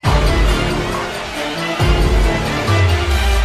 zombie.ogg